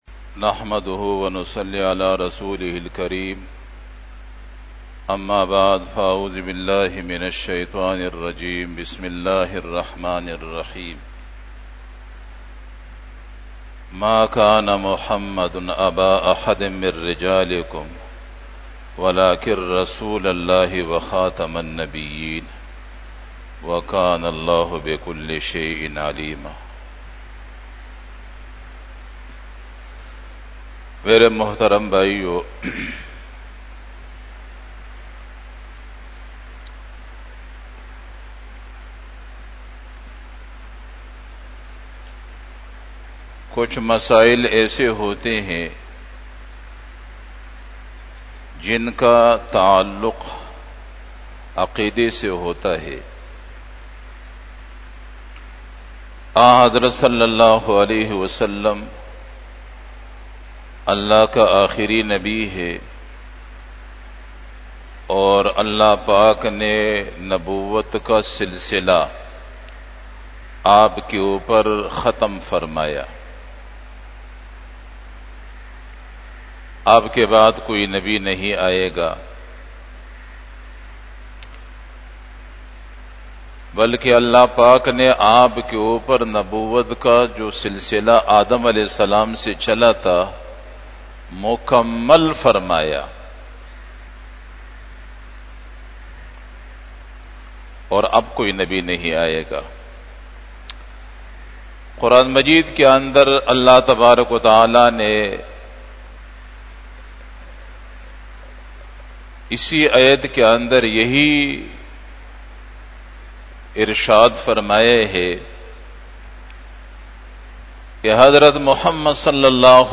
36 Bayan E Jummah 06 September 2024 (01 Rabi Ul Awwal 1446 HJ)